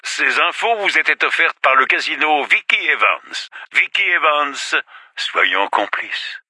M. New Vegas parlant du Casino de Vikki et Vance dans Fallout: New Vegas.